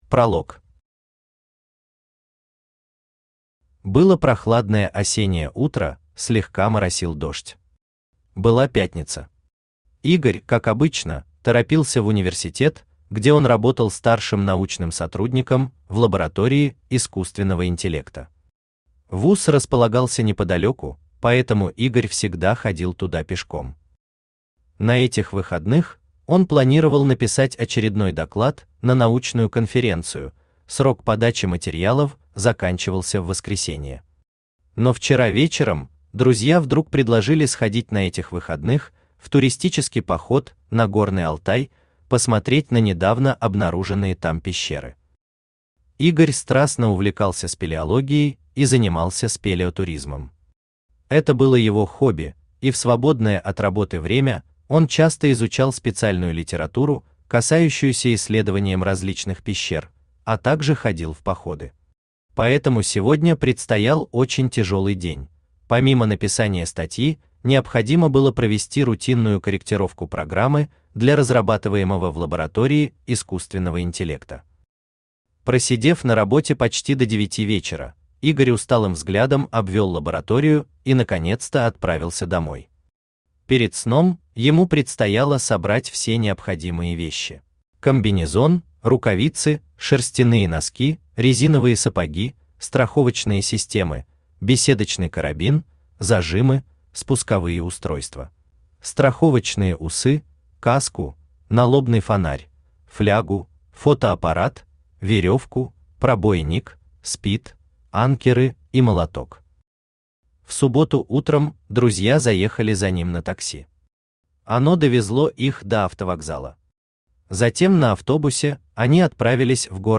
Аудиокнига Подземные миры Экзарии. Часть 1 | Библиотека аудиокниг
Часть 1 Автор Сергей Панов Читает аудиокнигу Авточтец ЛитРес.